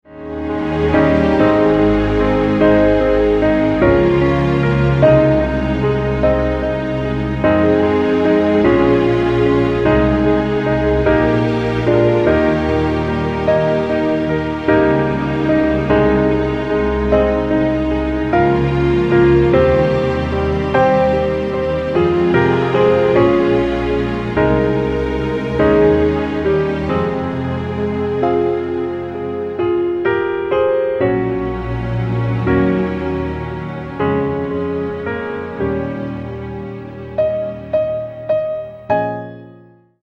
Piano - Strings - Low